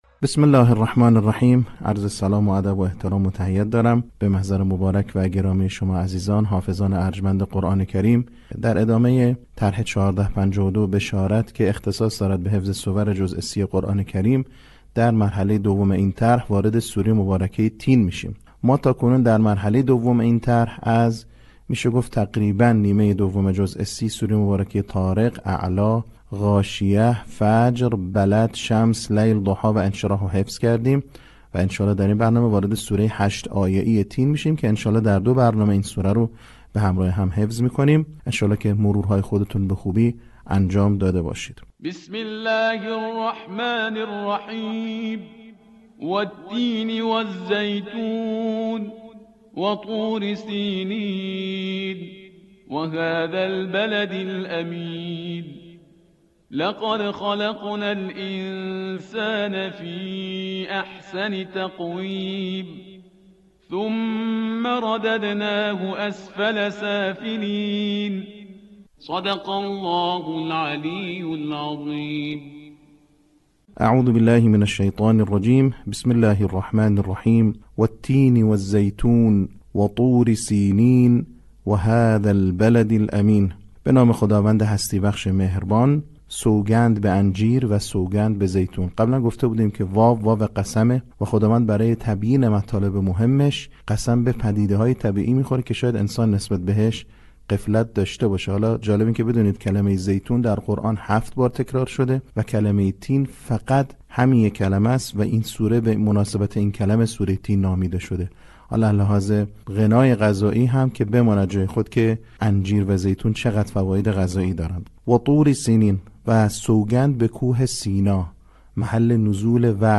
صوت | آموزش حفظ سوره تین